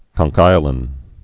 (kŏng-kīə-lĭn, kŏn-)